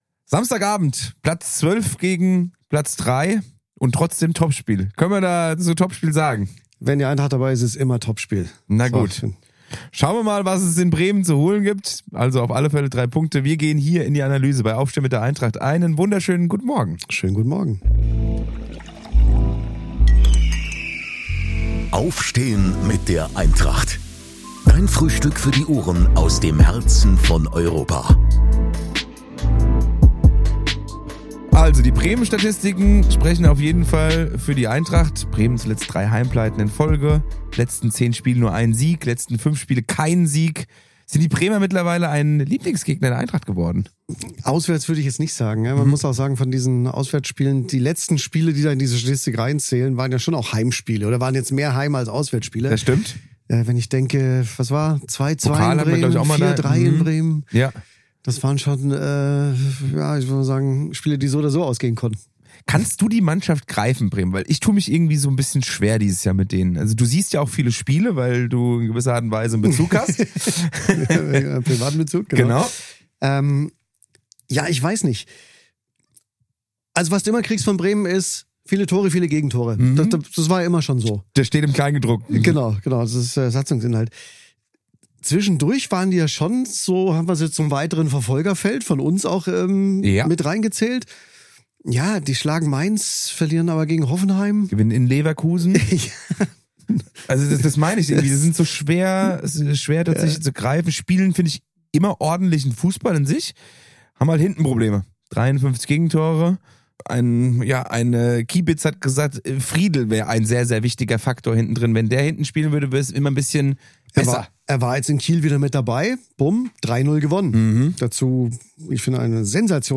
Im offiziellen Podcast des Klubs kommen die Menschen zu Wort, die für all das stehen und viel zu erzählen haben.